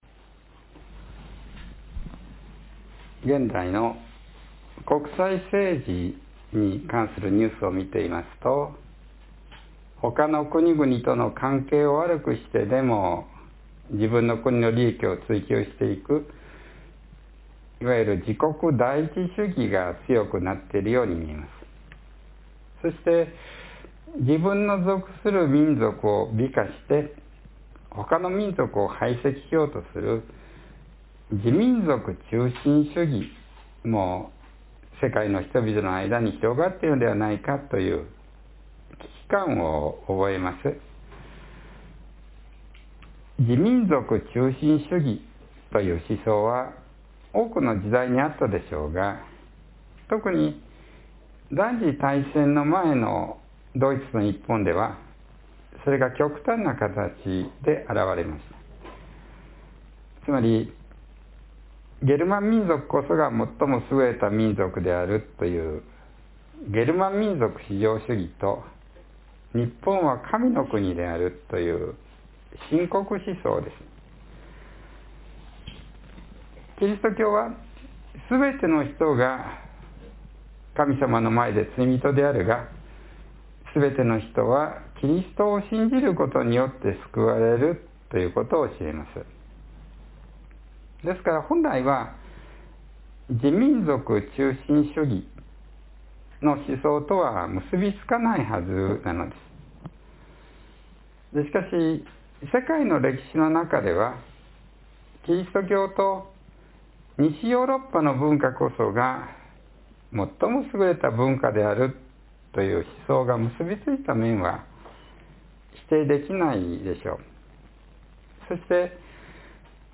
（10月20日の説教より）